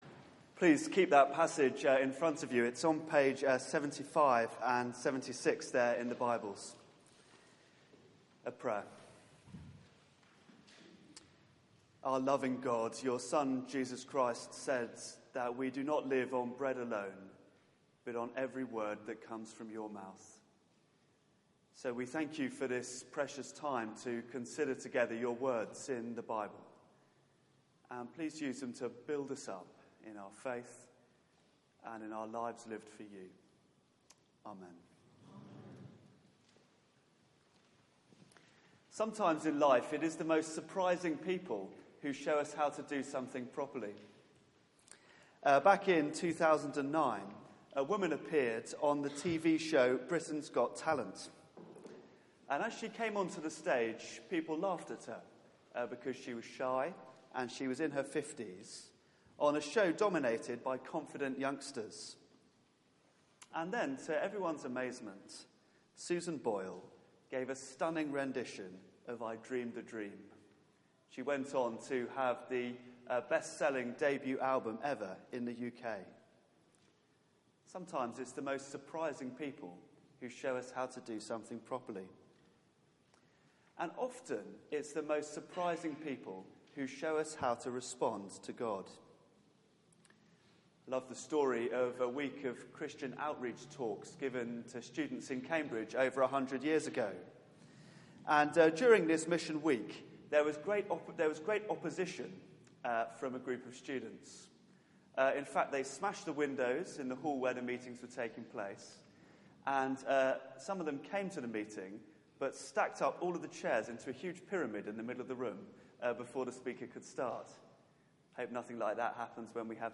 Passage: Exodus 18 Service Type: Weekly Service at 4pm Bible Text